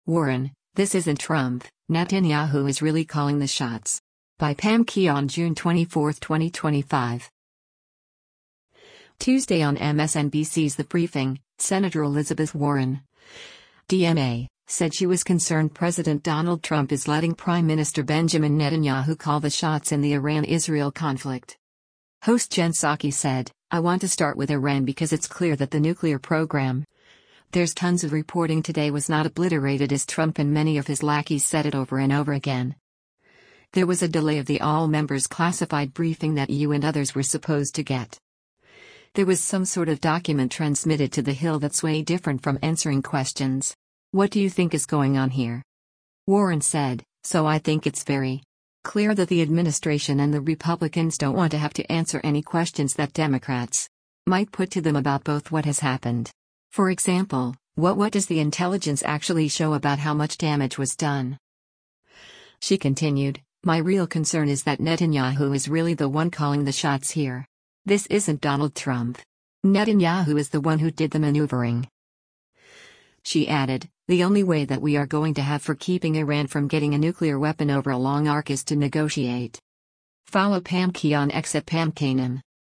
Tuesday on MSNBC’s “The Briefing,” Sen. Elizabeth Warren (D-MA) said she was concerned President Donald Trump is letting Prime Minister Benjamin Netanyahu call the shots in the Iran-Israel conflict.